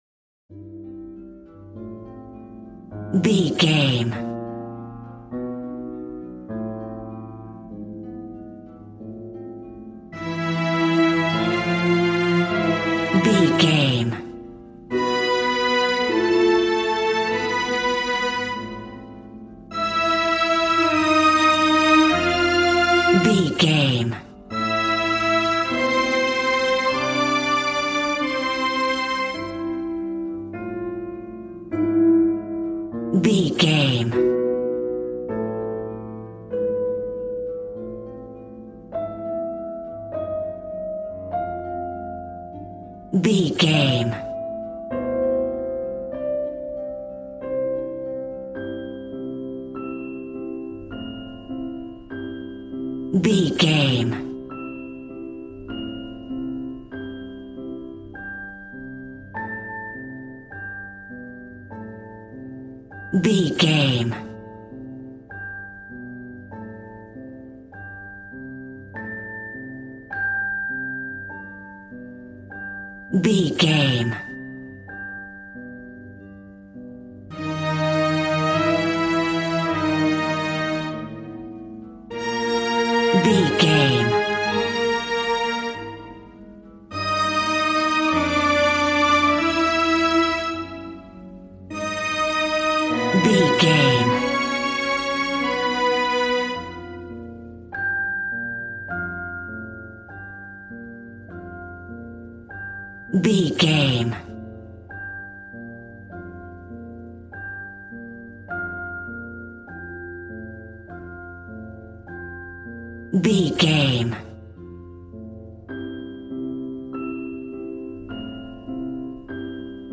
Regal and romantic, a classy piece of classical music.
Aeolian/Minor
regal
strings
violin